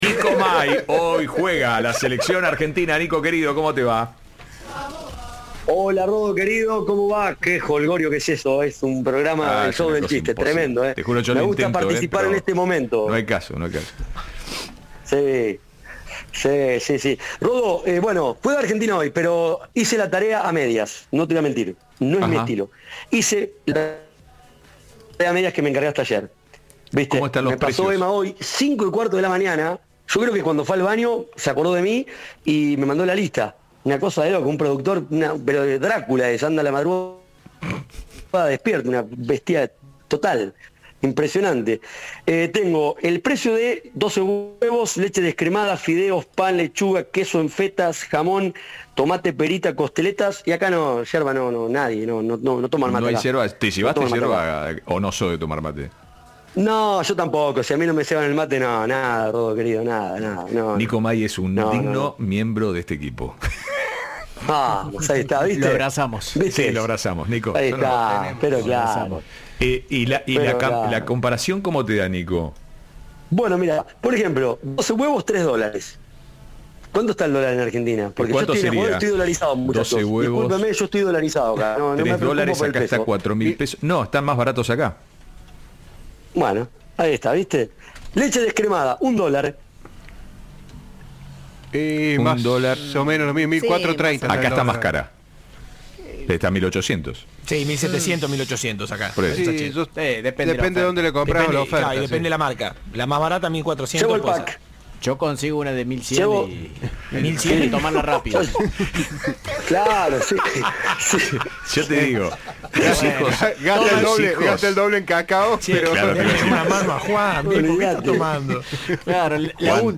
Cadena 3 está en Guayaquil para el duelo entre la selección de Scaloni ante la local por el cierre de las Eliminatorias.
Informe